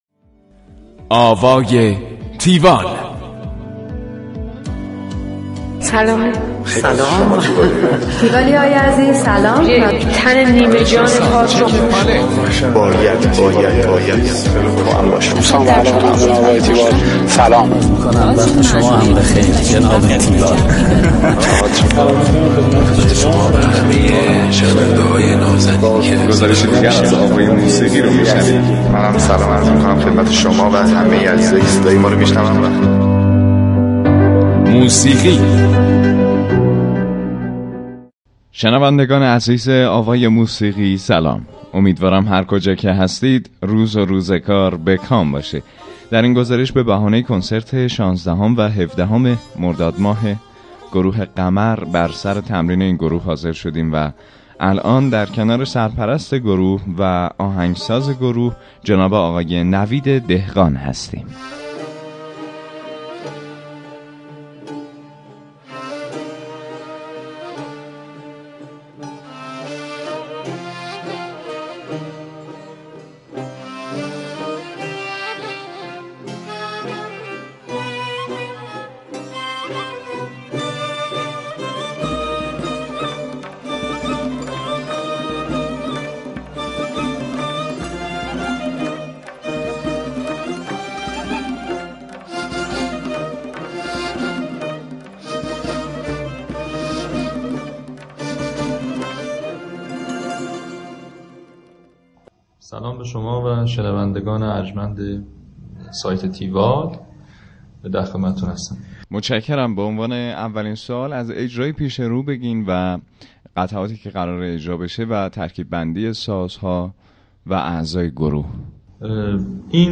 گفتگوی تیوال
گفتگو کننده